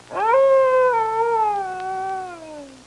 Coyote Whine Sound Effect
Download a high-quality coyote whine sound effect.
coyote-whine.mp3